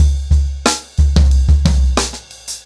Expositioning-90BPM.17.wav